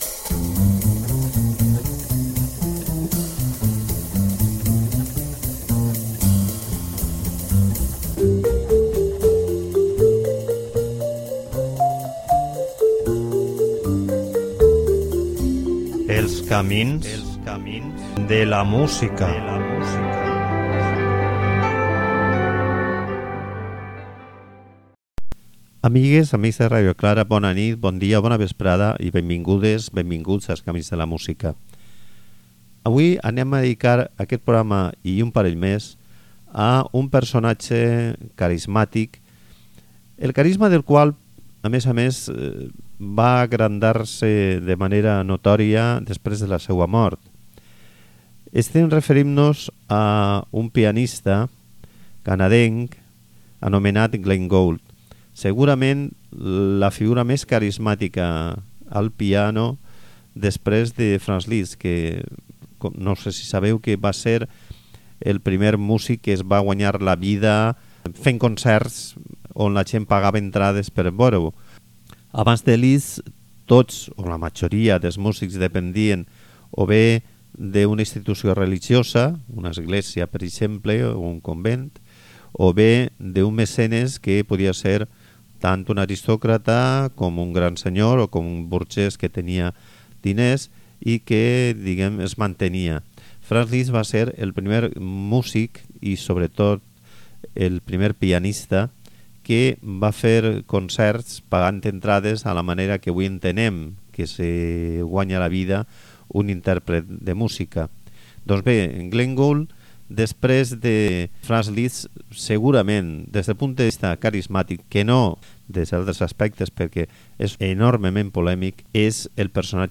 Al programa de hui l'escoltarem en un programa d'absoluta avantguarda de començament del segle XX: Berg, Schoenberg i Krenek. Atonalisme i dedecafonisme "a tutti plen".